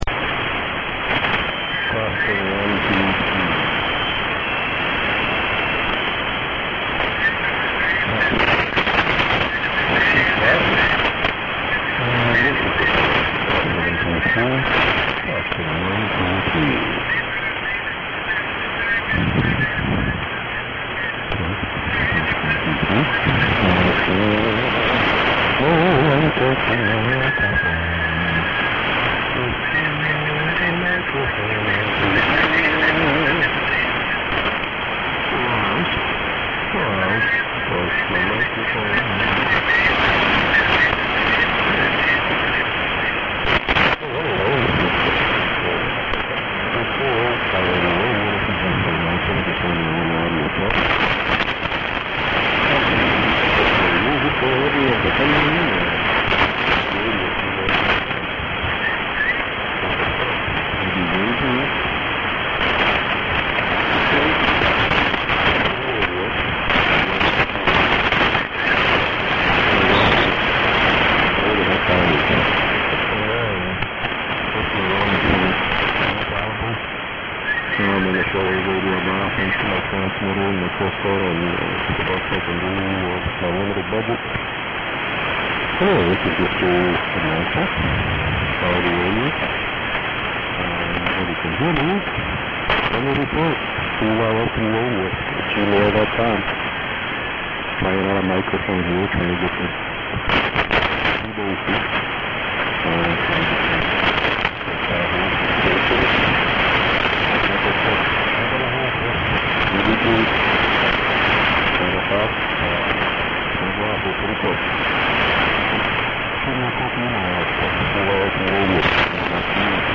SDR recording catch. On at 0008z with OM repeating test counts, then singing "I'm testing".
ID and gmail address around 0010z but I could not pull it out of the noise.